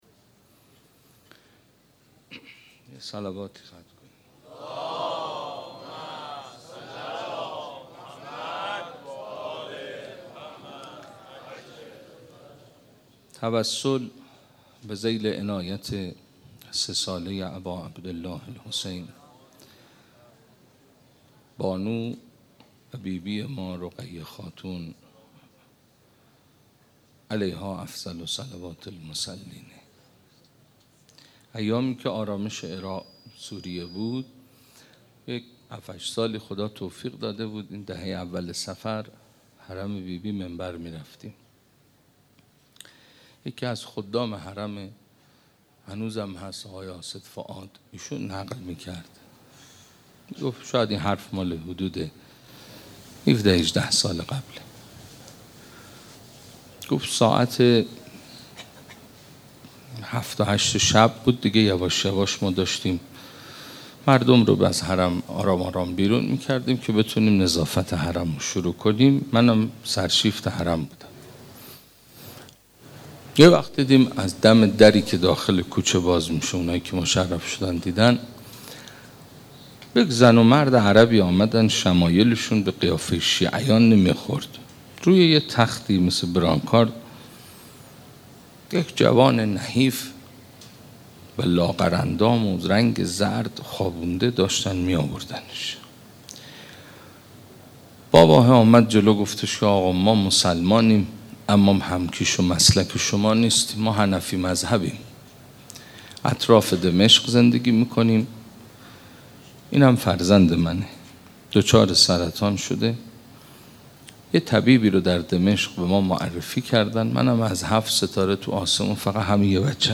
شب هجدهم ماه مبارک رمضان 1435 > سخنرانی